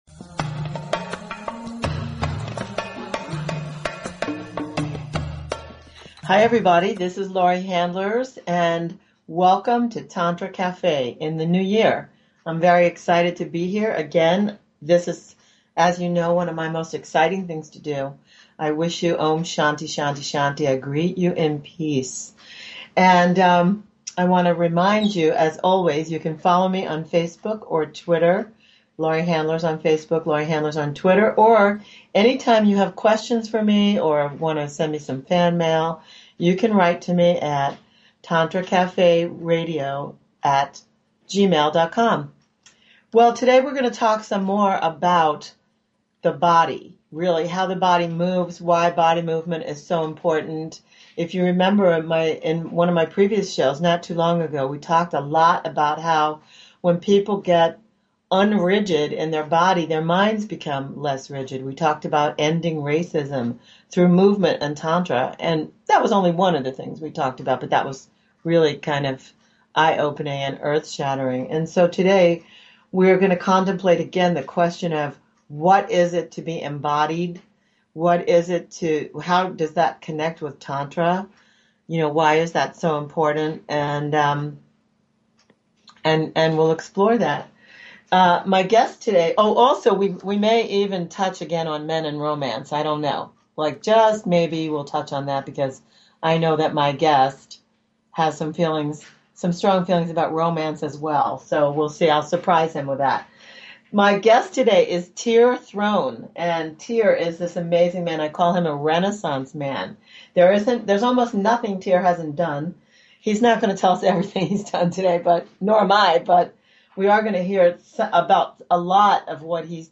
Talk Show Episode, Audio Podcast, Tantra_Cafe and Courtesy of BBS Radio on , show guests , about , categorized as
Movement is key. He takes us through a simple yet deep exercise.